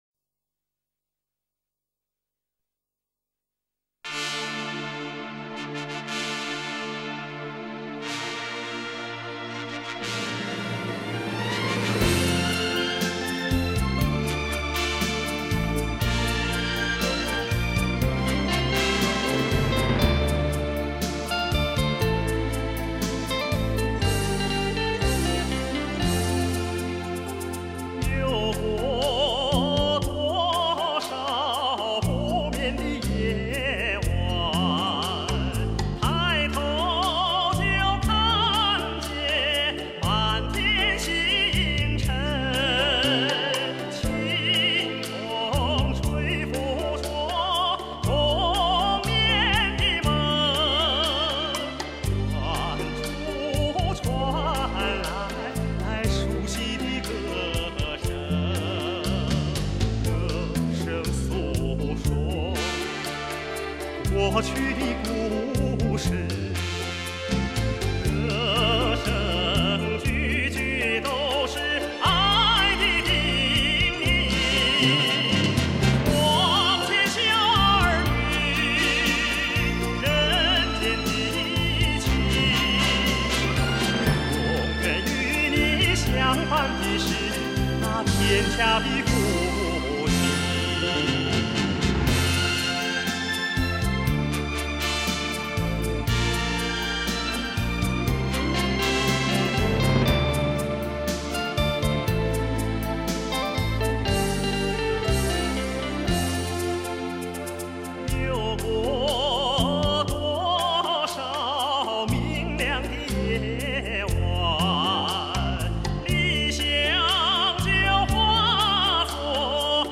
音质：DSD转MP3
他那明亮、通透、自然、洒脱的深情歌声打动了无数听众。
气势磅礴真情释放，